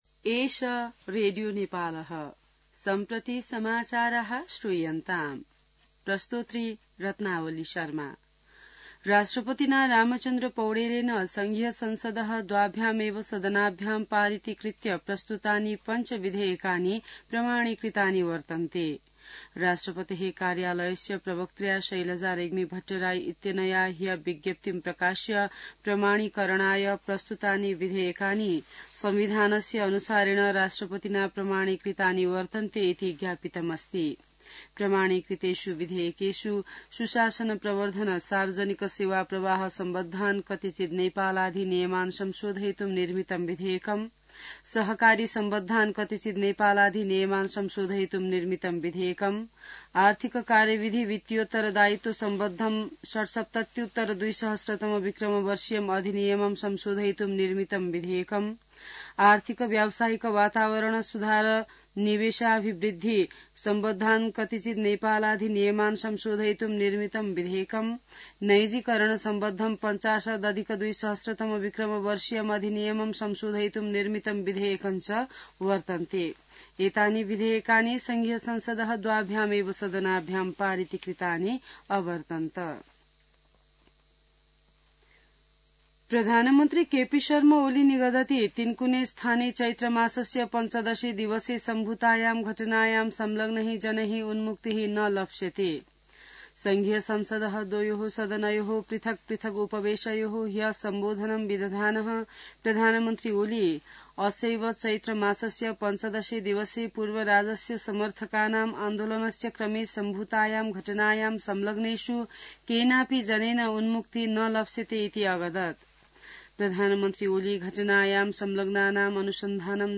संस्कृत समाचार : १९ चैत , २०८१